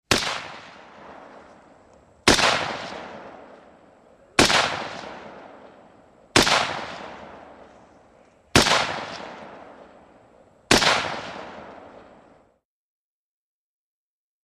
AutoMachGunShots PE706101
WEAPONS - MACHINE GUNS HEAVY SQUAD AUTOMATIC WEAPON: EXT: Single shots with echo.